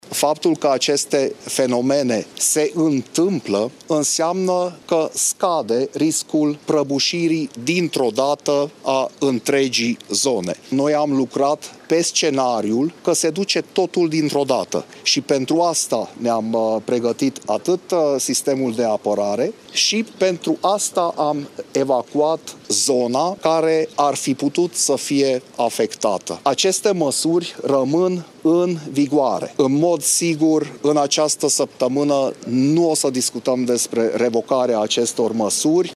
Acesta a vorbit astăzi despre cât de mare este riscul de a se produce o prăbușire bruscă în zona minei de sare, în condițiile în care craterele apărute s-au tot mărit.